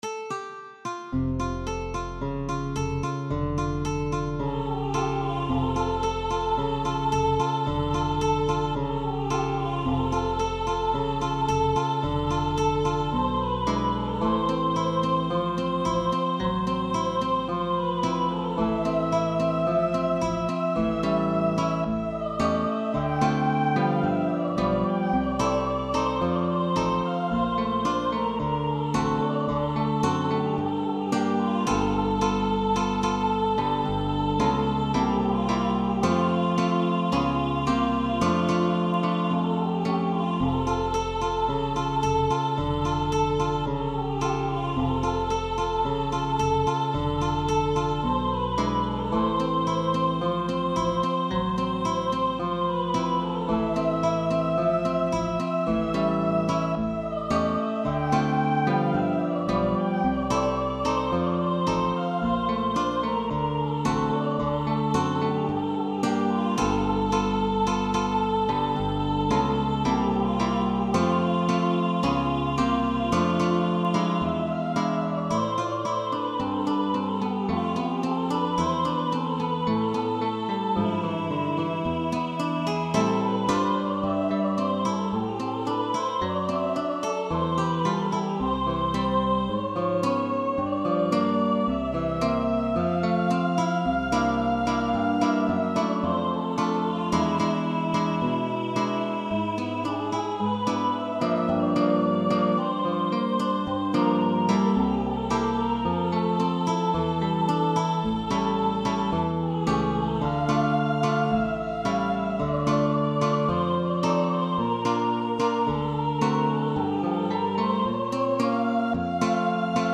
Voice and guitar sheetmusic with optional bass.